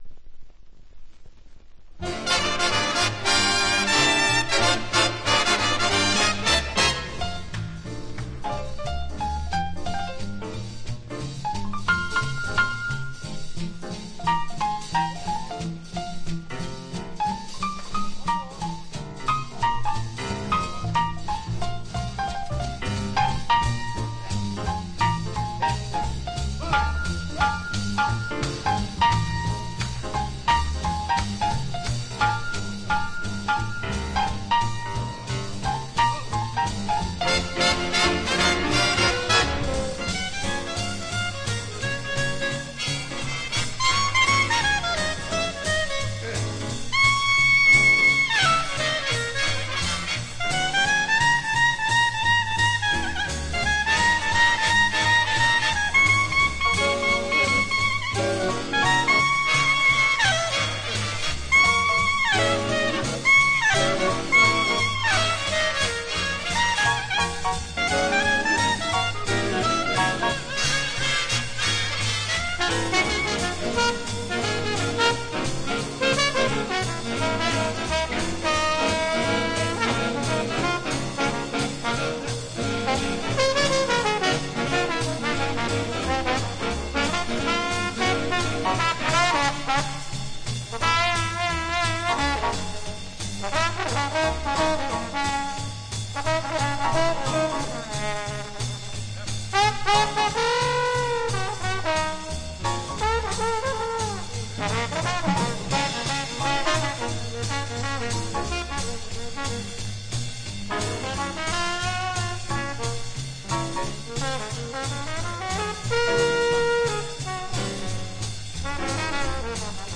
Жанр: Jazz, Big Band